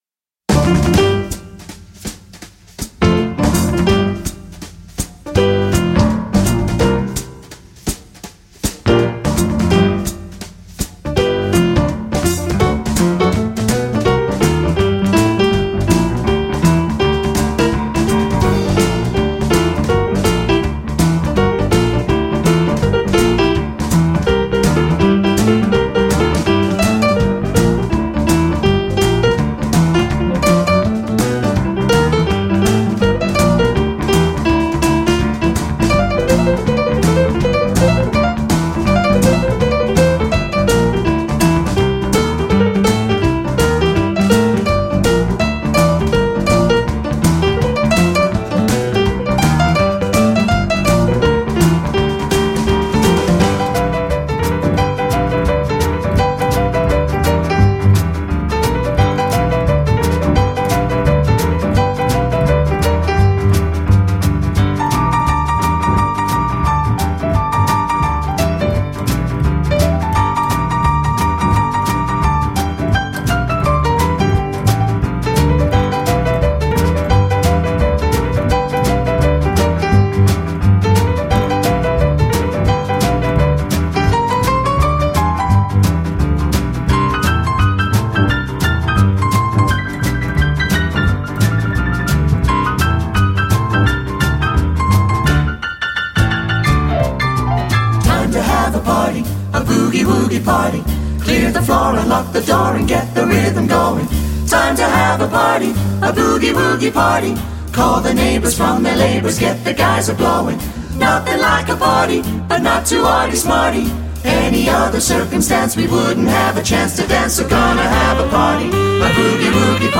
Буги – это музыка со средним и быстрым темпоритмом.